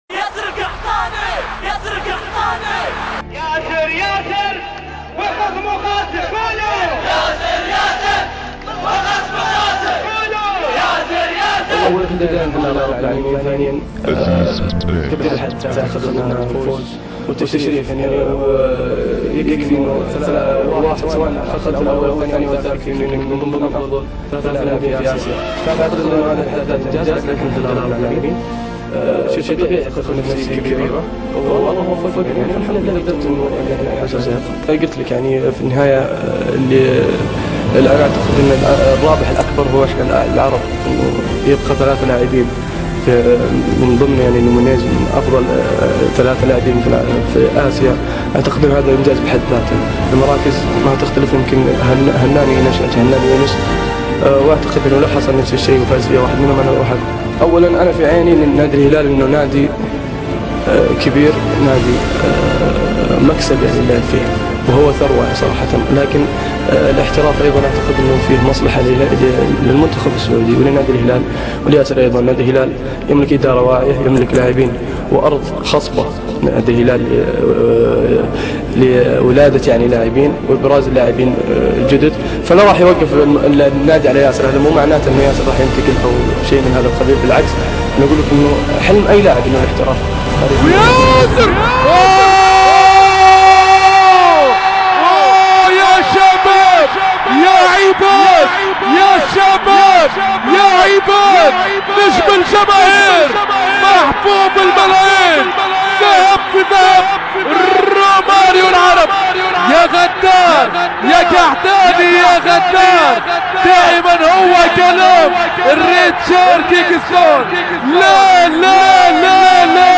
[ استايل مع موسيقـى ]